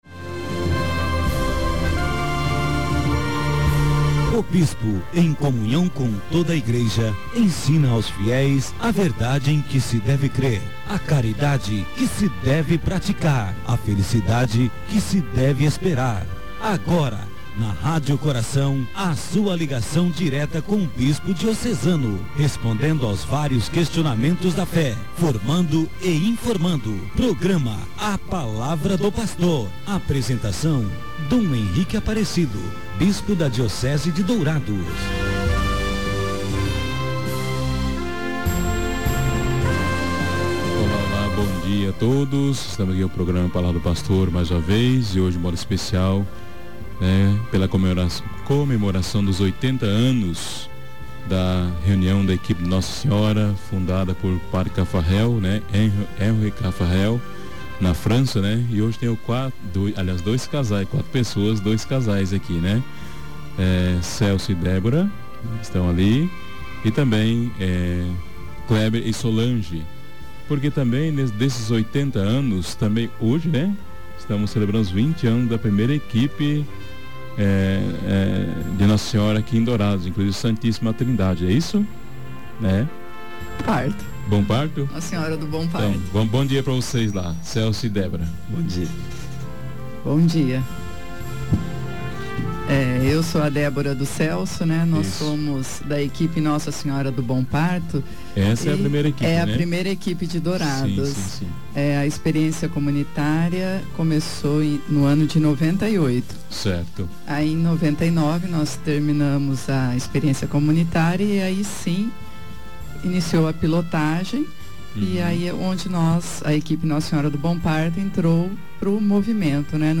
Dom Henrique, bispo da Diocese de Dourados, apresentou nesta sexta-feira (01/03) seu programa semanal 'A Palavra do Pastor'. Juntamente com convidados, falaram sobre os 80 anos das Equipes de Nossa Senhora, comemorado na última segunda-feira (25/02).